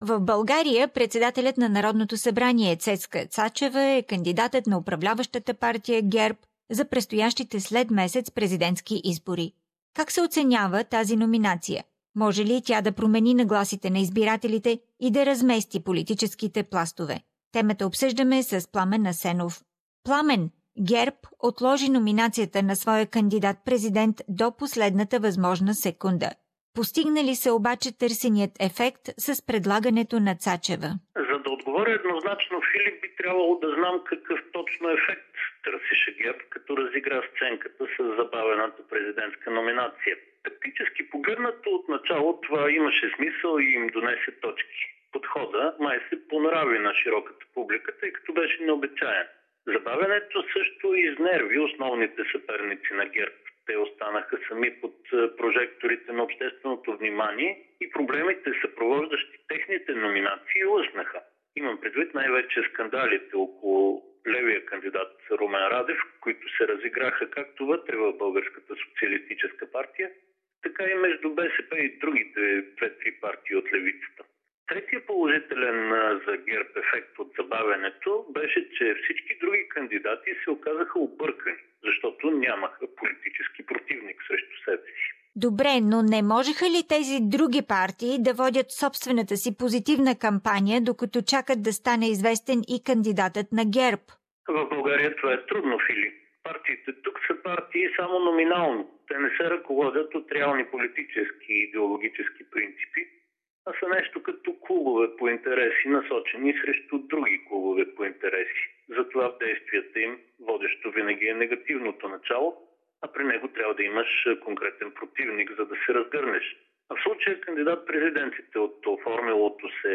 Политически анализ